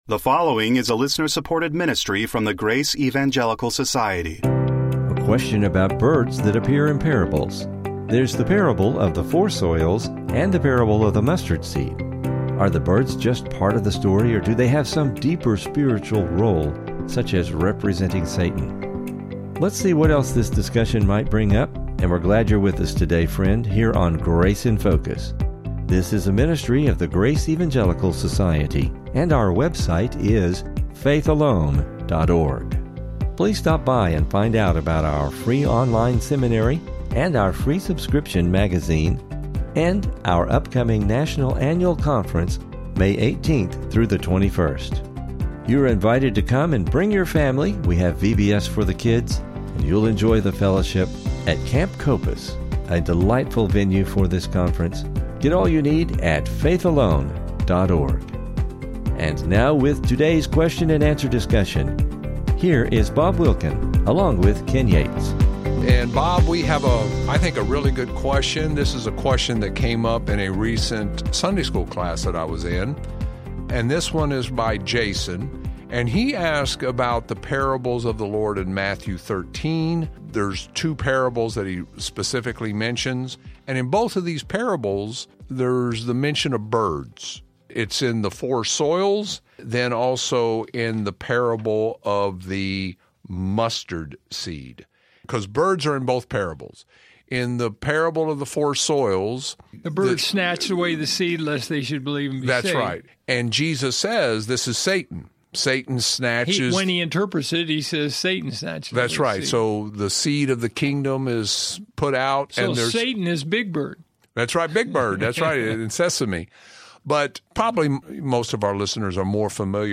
Please listen for an informative discussion and never miss an episode of the Grace in Focus Podcast!